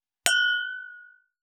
300シャンパングラス,ワイングラス乾杯,イタリアン,バル,フレンチ,夜景の見えるレストラン,チーン,カラン,キン,コーン,チリリン,カチン,チャリーン,クラン,カチャン,クリン,シャリン,チキン,コチン,カチコチ,
コップ